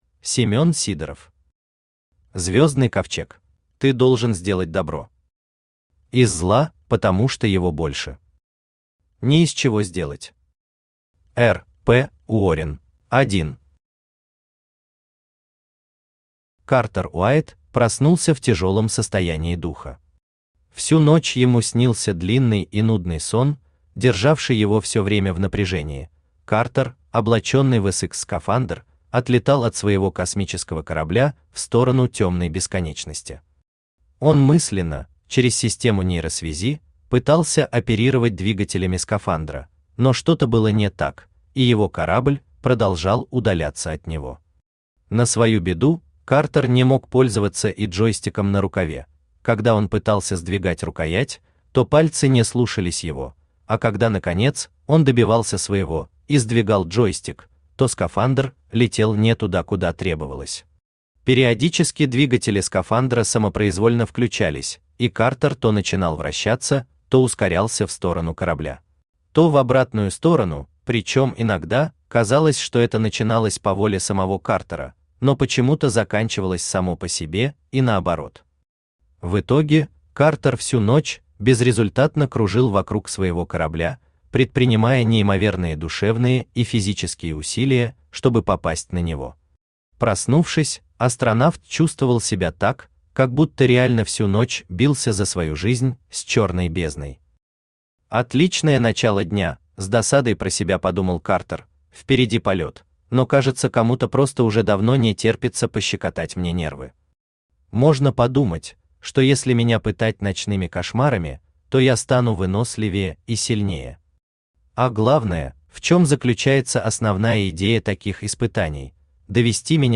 Аудиокнига Звездный ковчег | Библиотека аудиокниг
Aудиокнига Звездный ковчег Автор Семен Сидоров Читает аудиокнигу Авточтец ЛитРес.